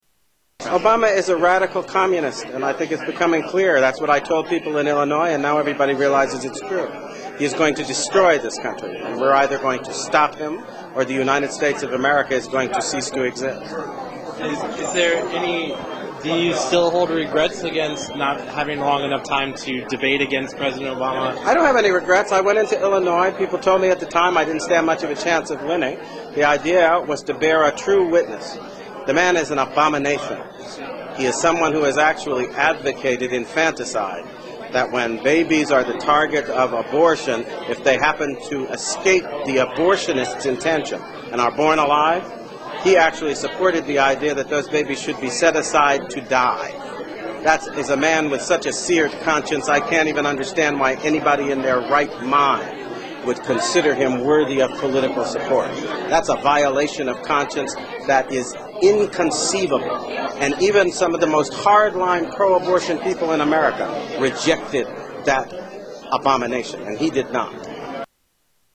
Tags: Political Alan Keyes audio Alan Keyes Alan Keyes Speeches The Tea Part